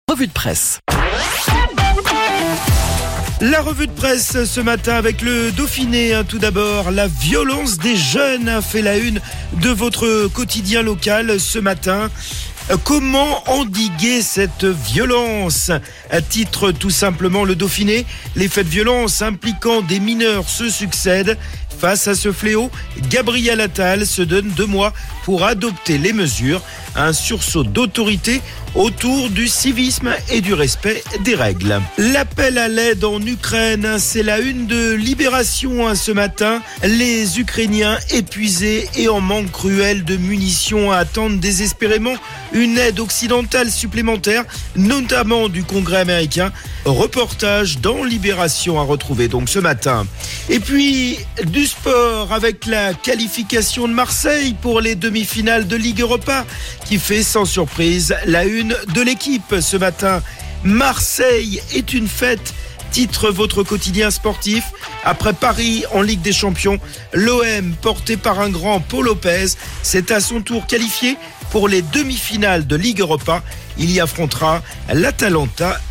La revue de presse